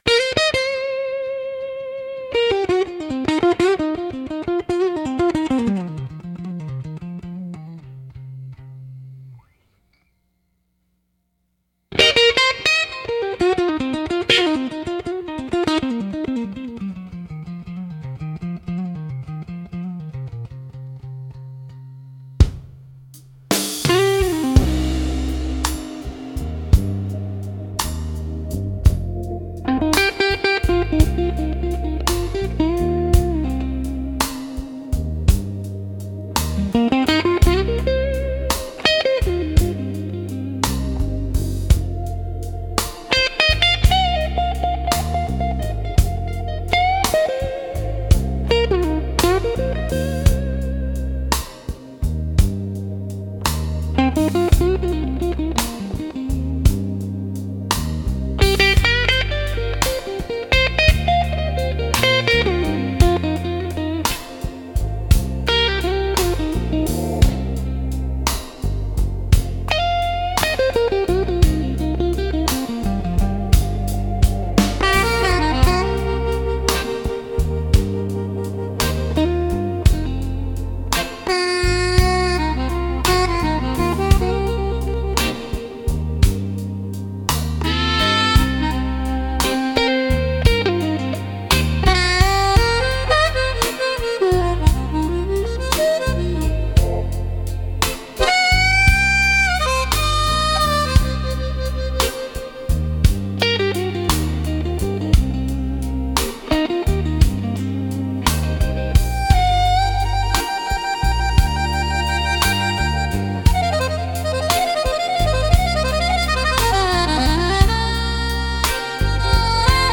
ギターやハーモニカによる哀愁のあるメロディとリズムが、深い感情表現を生み出します。
しっとりとした雰囲気を求める場面で活用されるジャンルです。